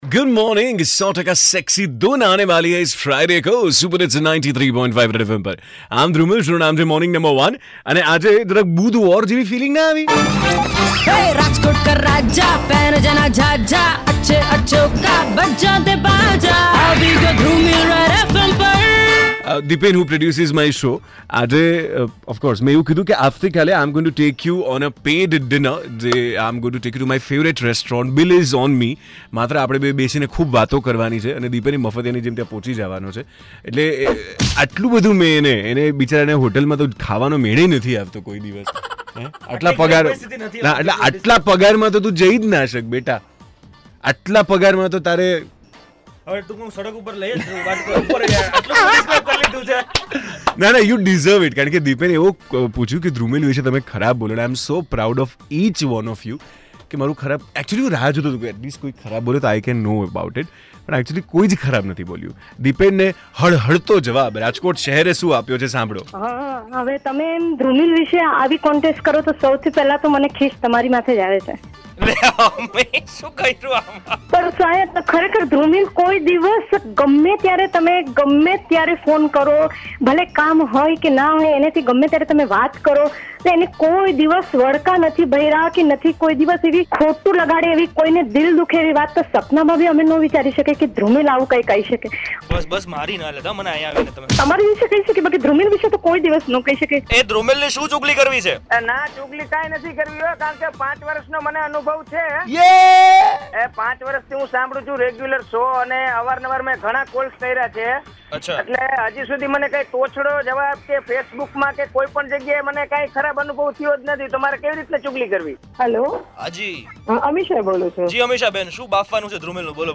Listeners sharing their answers